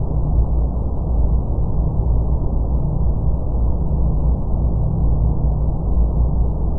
engine_hum_loop.wav